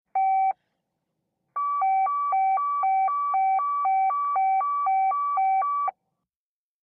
Cell phone dialing individual keys ( last 3 are # and * )